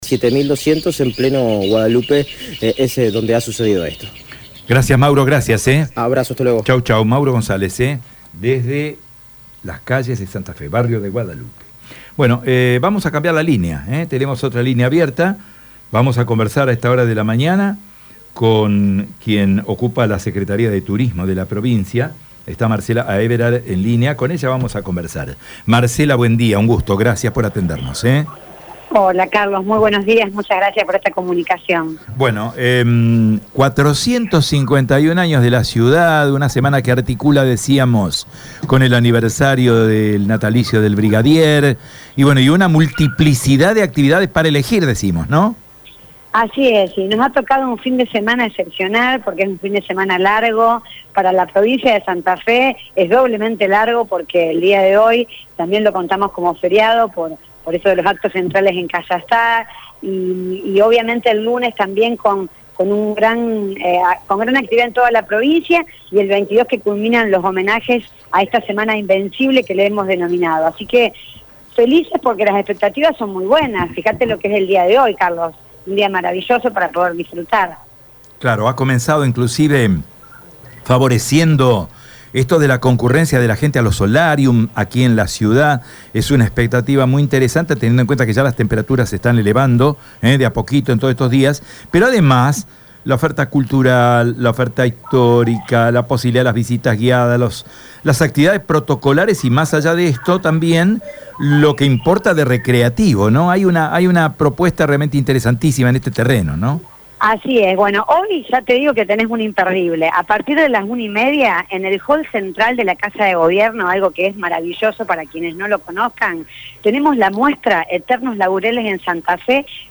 En el marco del aniversario 451 de la ciudad de Santa Fe, Radio EME dialogó con Marcela Aeberhard, Secretaria de Turismo de la Provincia, por este fin de semana extra largo para los santafesinos. La funcionaria destacó, principalmente, las actividades de la semana invencible y aseguró que hay grandes expectativas.